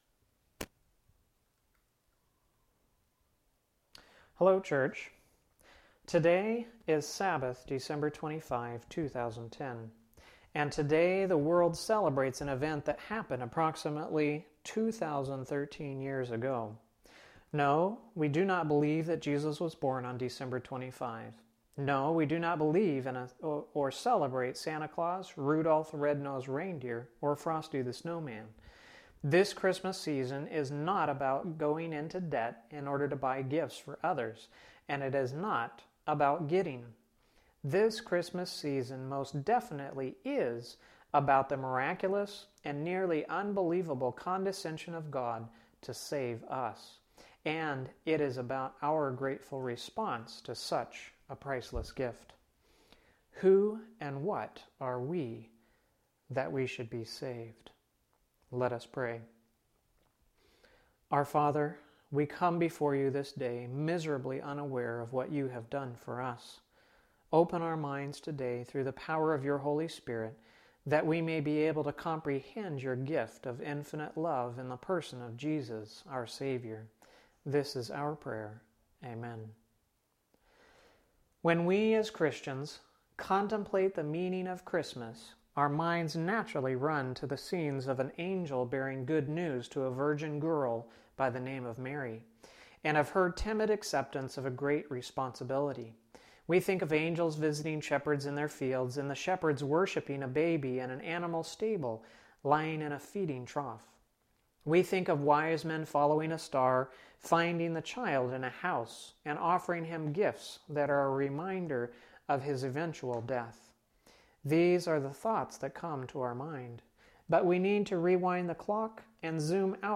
This sermon was delivered at the Patna Company on Christmas Day, 2010, however, the recording failed on that day and when I got home all there was was some clicking sounds.
So the actual version you hear here is a re-recorded version I did at home later.